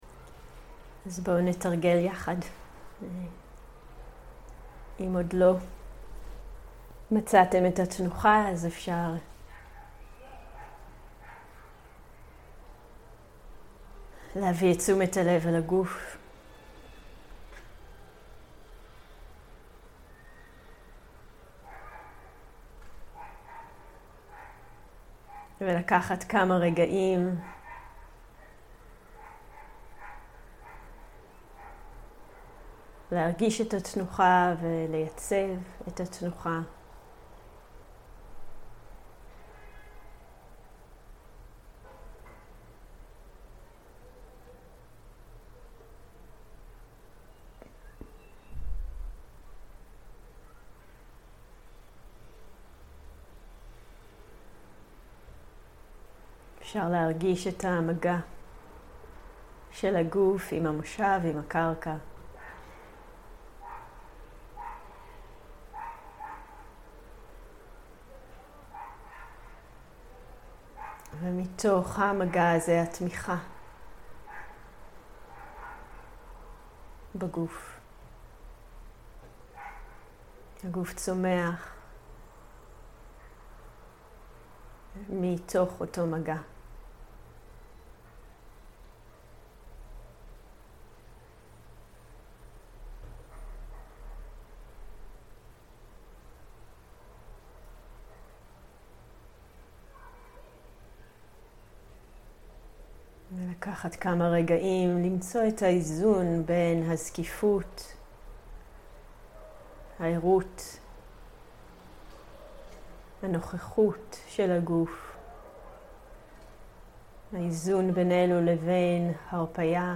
סוג ההקלטה: מדיטציה מונחית
איכות ההקלטה: איכות גבוהה